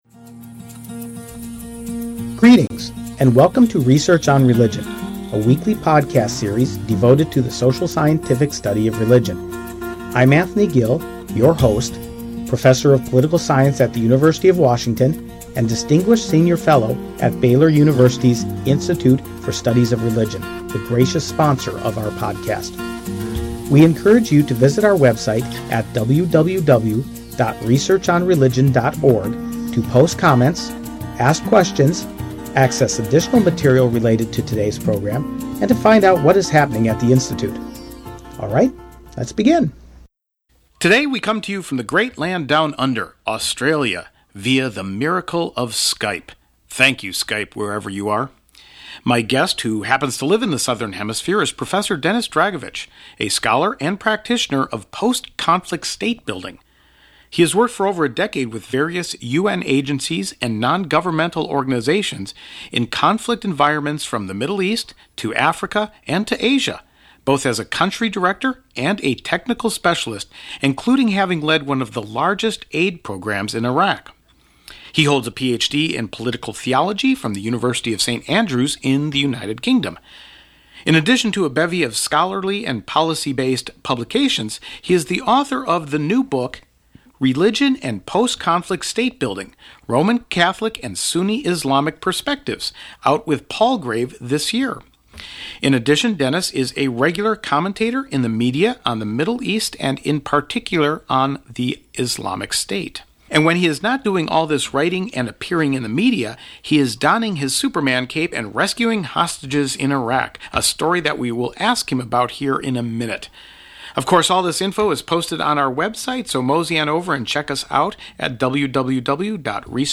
He begins the interview by telling us how he had to rescue one of his aid work